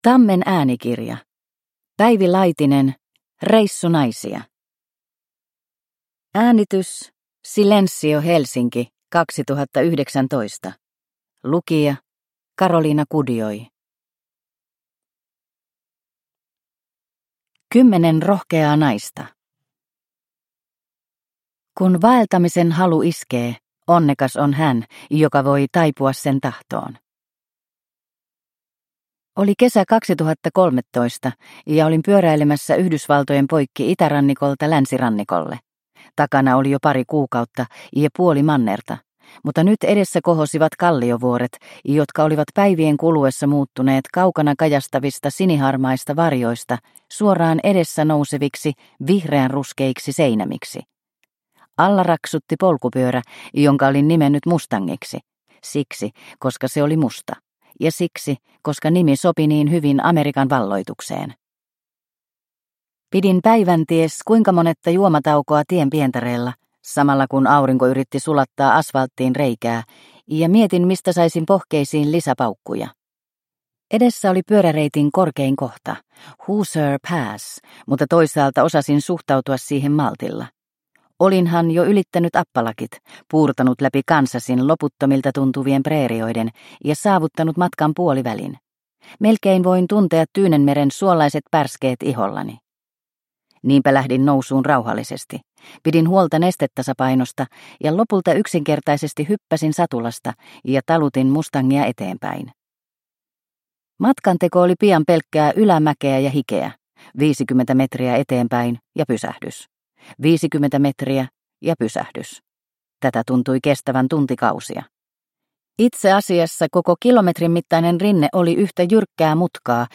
Reissunaisia – Ljudbok